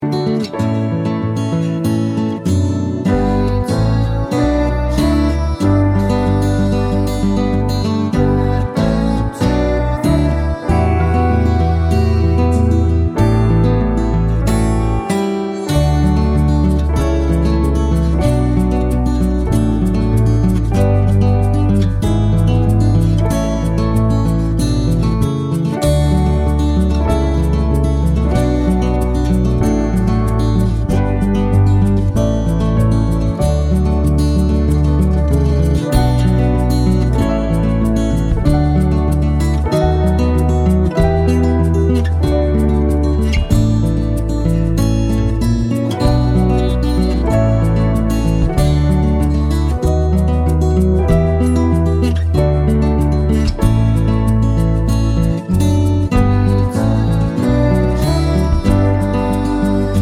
Spoken Part Cut Christmas 2:50 Buy £1.50